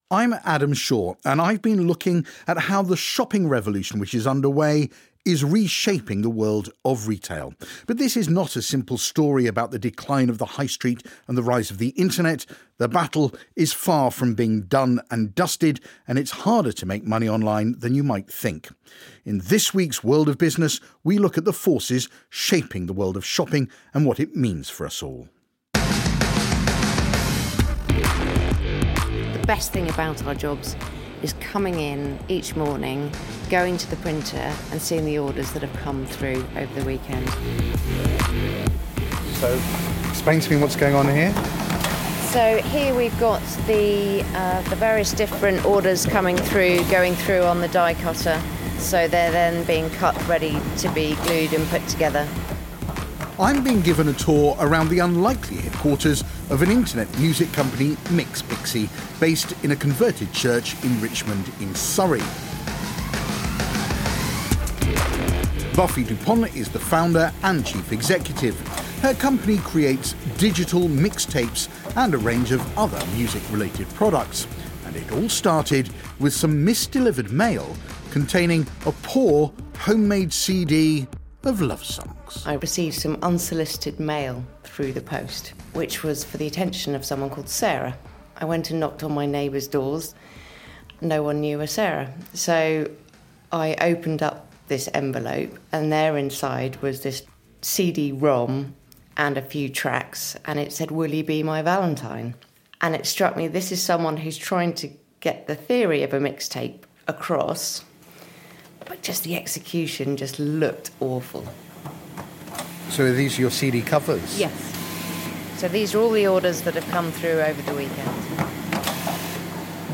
You can listen to the full BBC podcast below ‘The Business of Clicks’ played on BBC Radio 4 as part of their ‘In Business’ series.